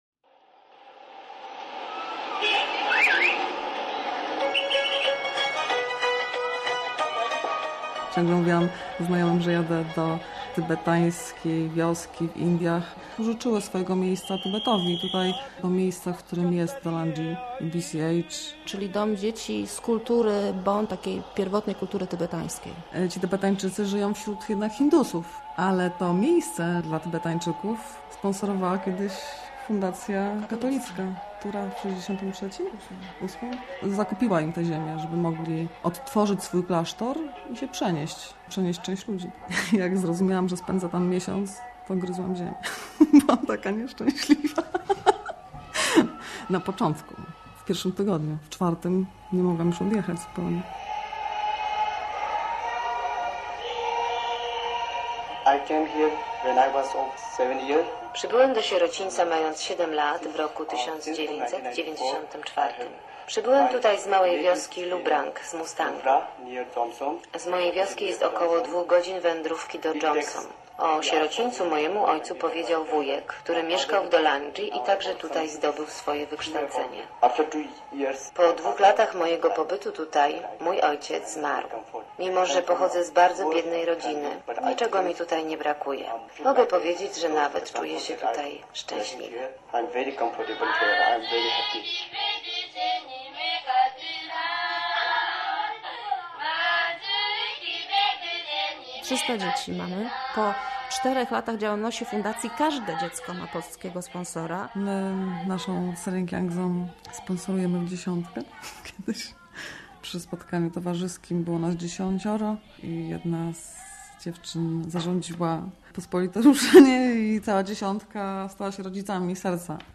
Są naukowcami, przedsiębiorcami, lekarzami, dziennikarzami - zwykłymi ludźmi, którzy przejęli się losem najbiedniejszych z biednych - tybetańskich dzieci z wioski Dolanji w indyjskich Himalajach. Reportaż